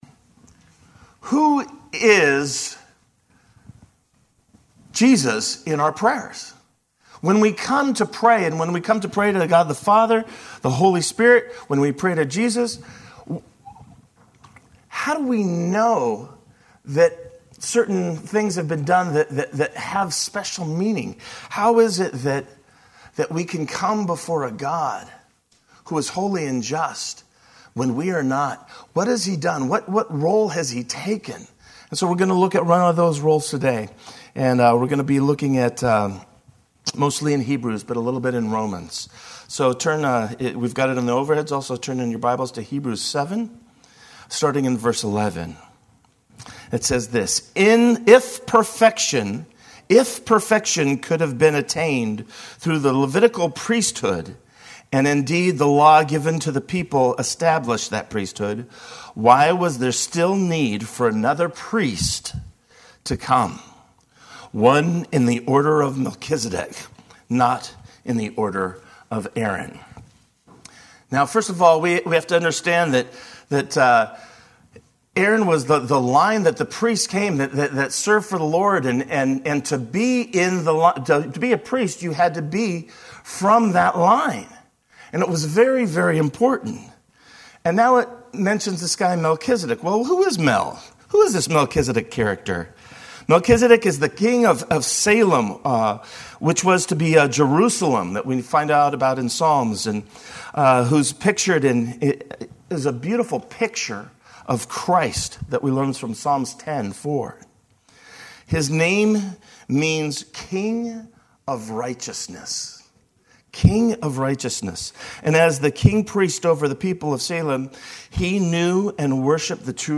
Sermon-11-3-19.mp3